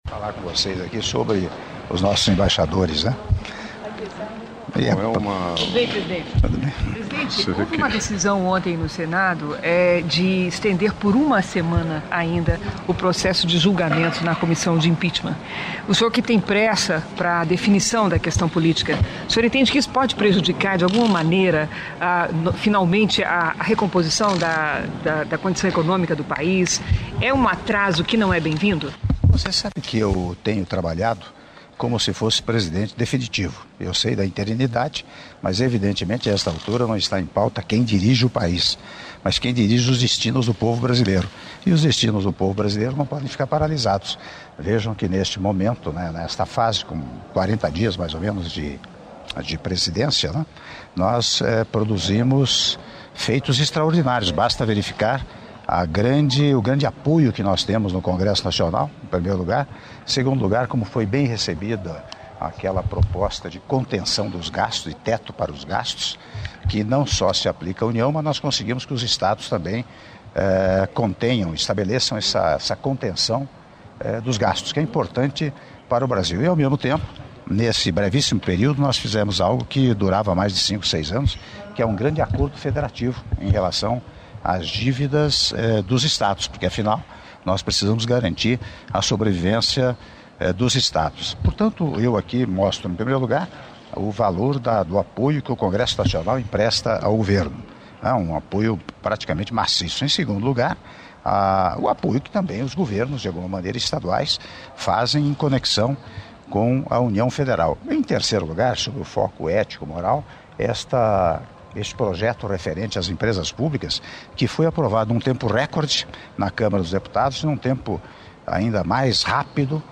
Áudio da entrevista coletiva concedida pelo Presidente da República em exercício, Michel Temer, após cerimônia de entrega de credenciais de embaixadores estrangeiros residentes em Brasília - Brasília/DF (03min02s)